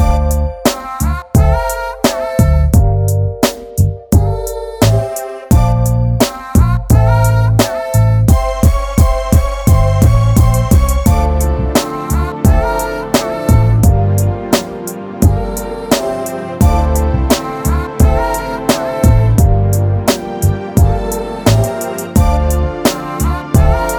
no Backing Vocals R'n'B / Hip Hop 3:50 Buy £1.50